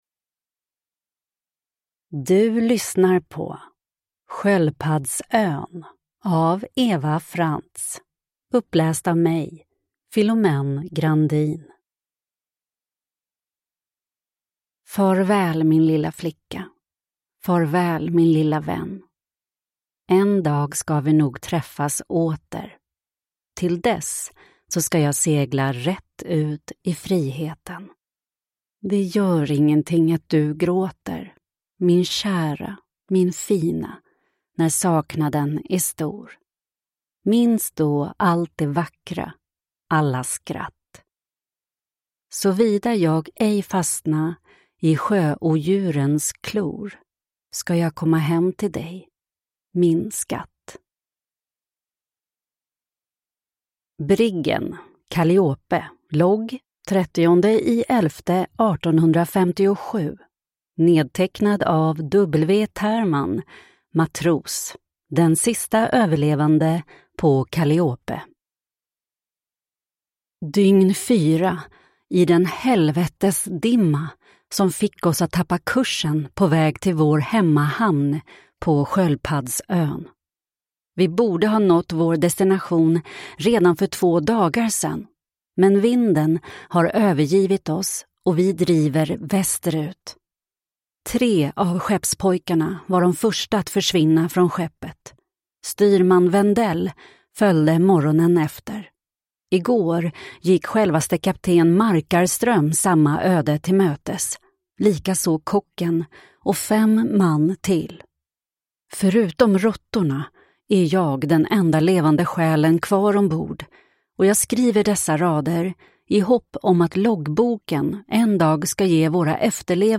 Sköldpaddsön – Ljudbok